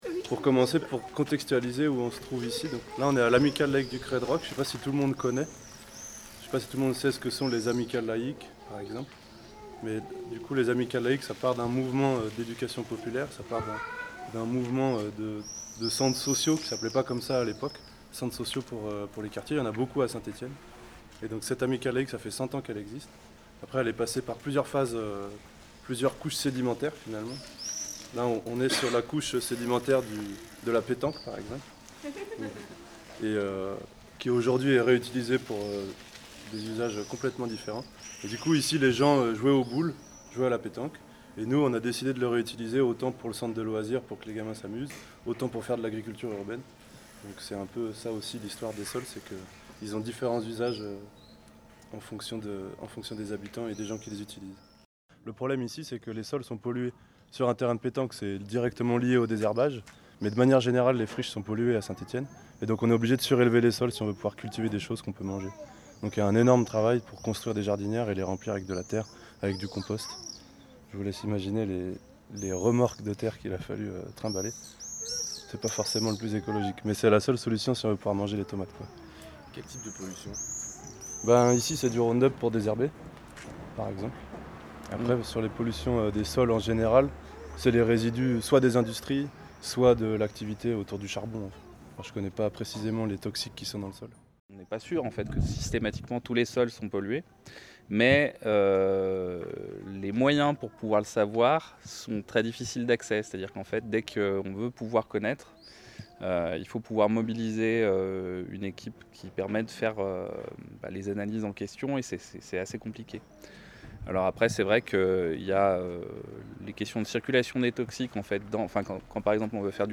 Cette baladodiffusion tente de retransmettre les échanges qui ont eu lieu au fil de cette traversée de la Coline entre l’Amicale Laïque du Crêt de Roch et le site Charvin en passant par les Jardins familiaux.